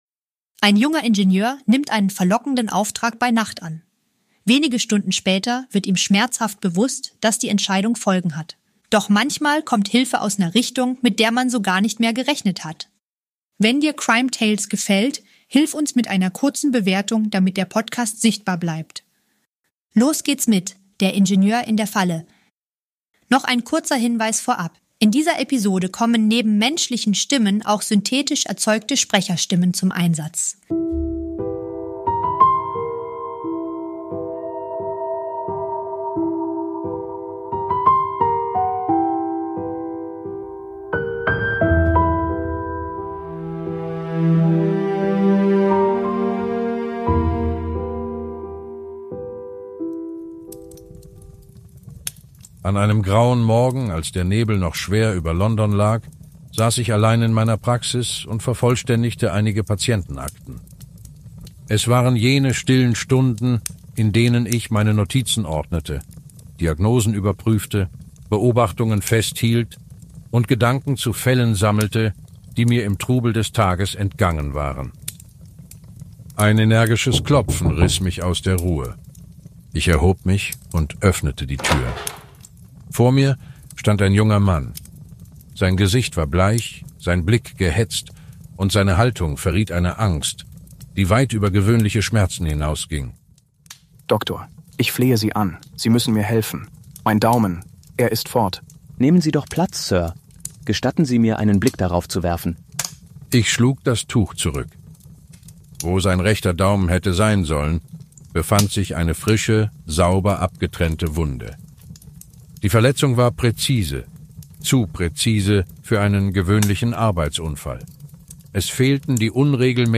- In dieser Produktion kommen neben unseren eigenen Stimmen auch synthetische Sprecherstimmen zum Einsatz.